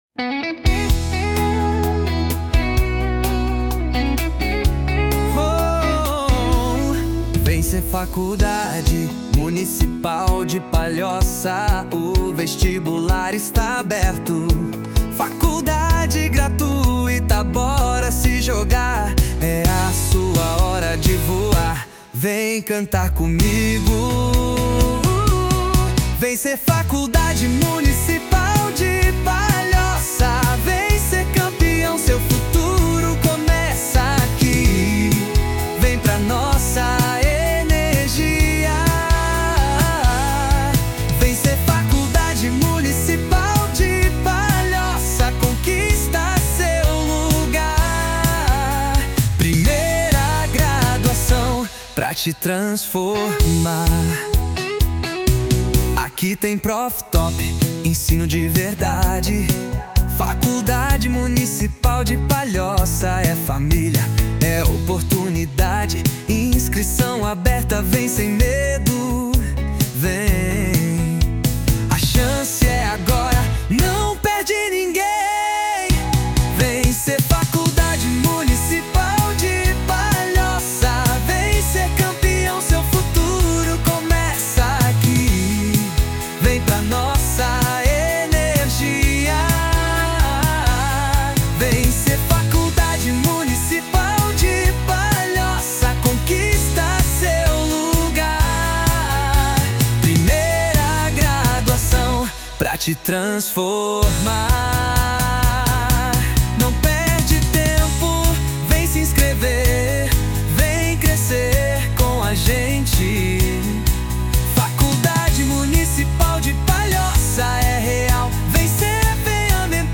2025-04-27 21:01:21 Gênero: Pop Views